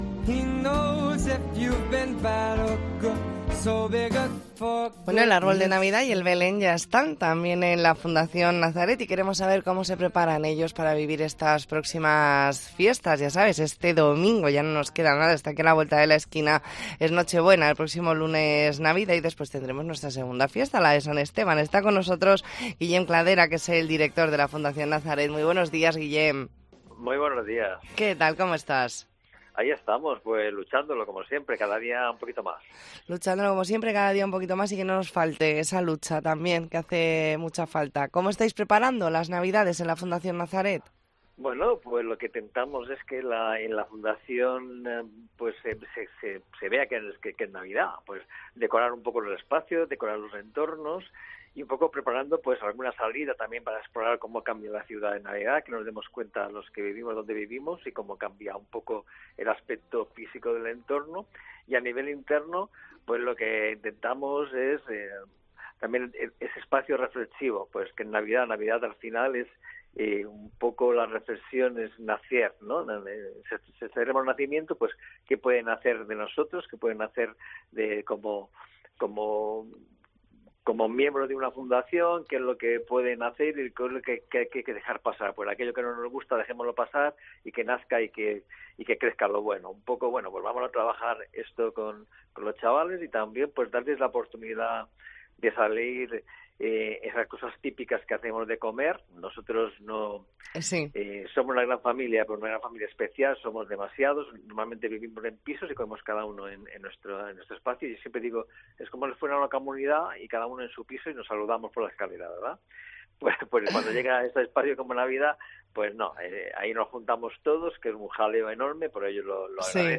Entrevista en La Mañana en COPE Más Mallorca, martes 19 de diciembre de 2023.